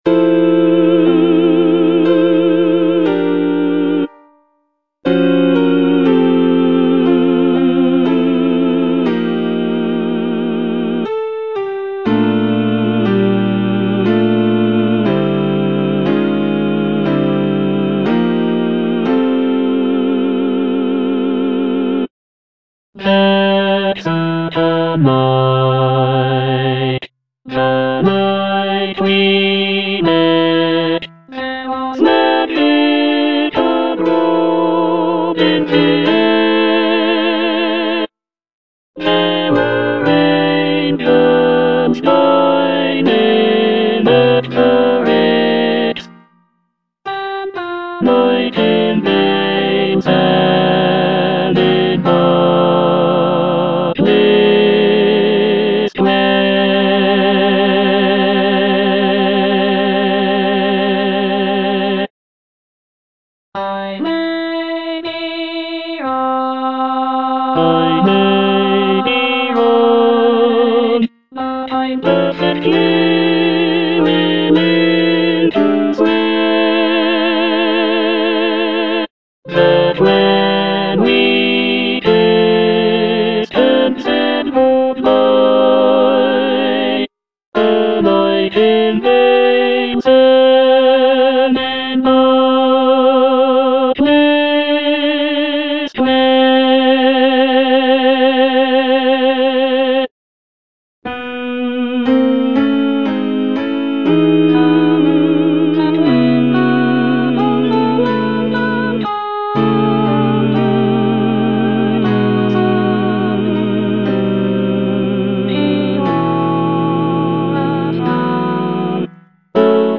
Tenor (Emphasised voice and other voices)